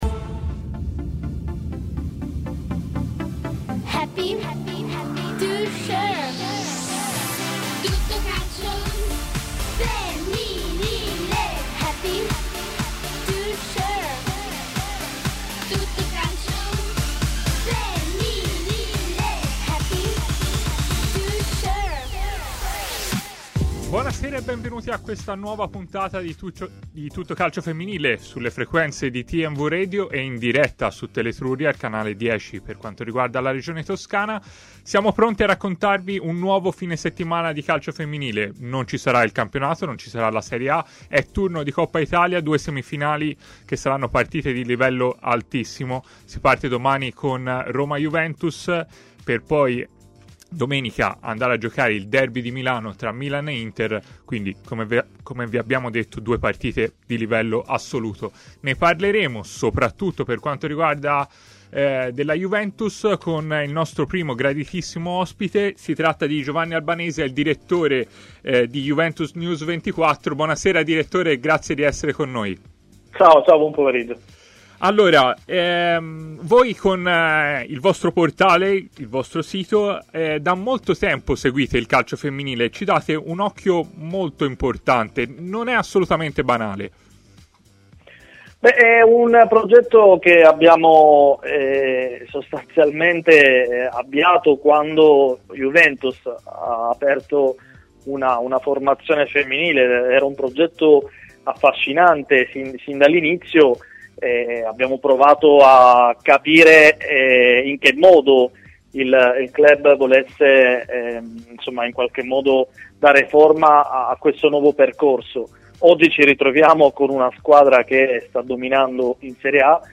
ha parlato in diretta a TMW Radio, nel corso della trasmissione Tutto Calcio Femminile